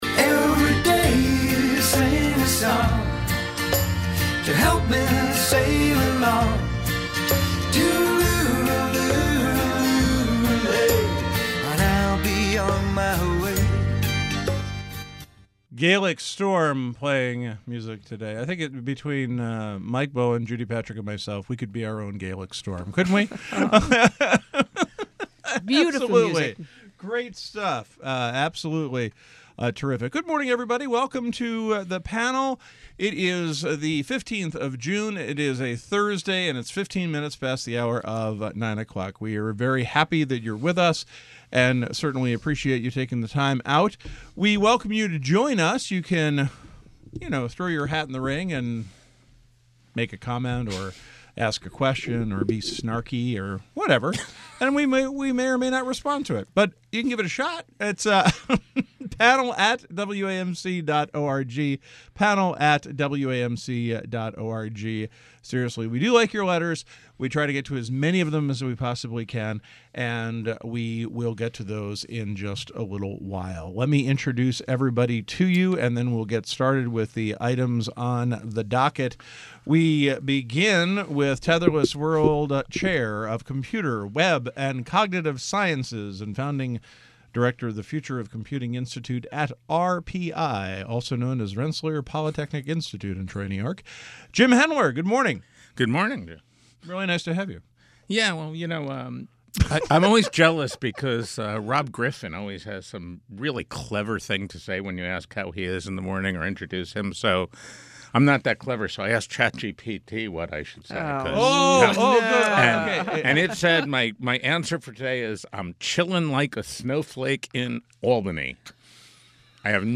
6/15/23 RT Panel